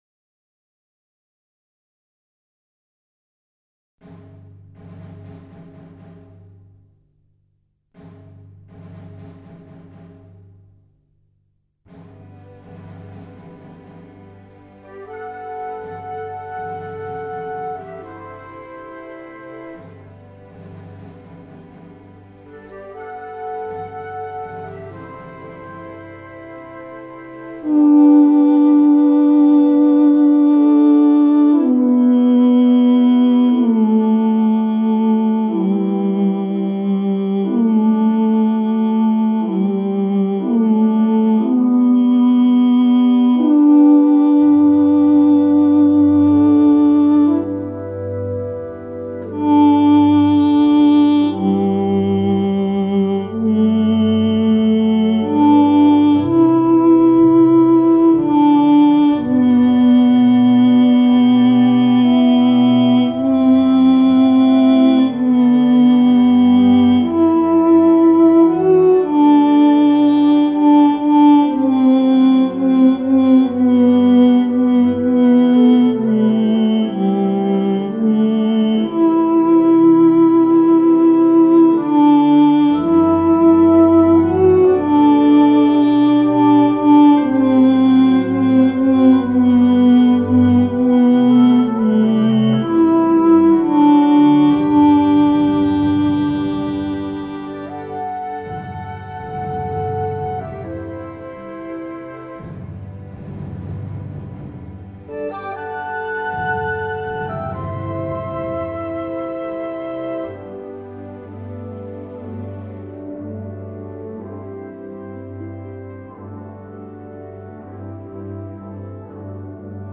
Lo ULTIMO Tenores
Bandas Sonoras de Películas BSO